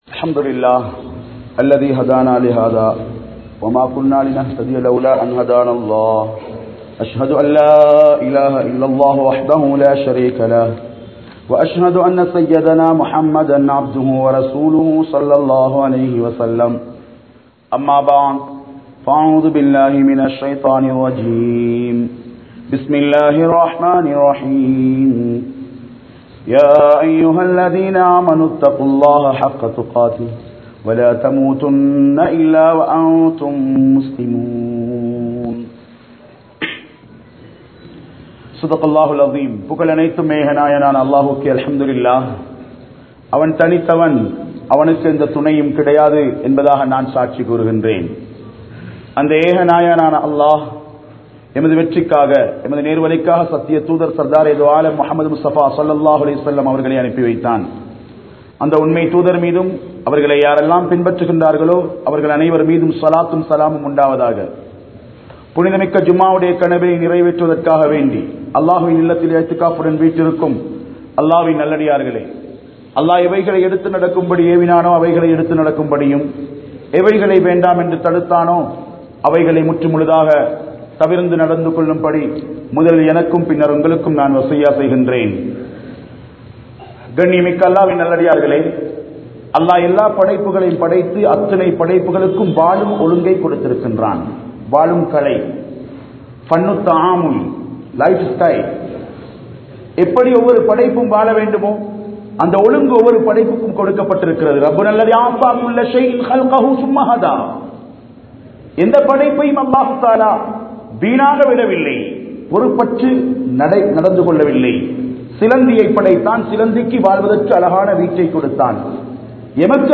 Nuweraliya Paavam Seium Idama? (நுவரெலியா பாவம் செய்யும் இடமா?) | Audio Bayans | All Ceylon Muslim Youth Community | Addalaichenai
Kabeer Jumua Masjith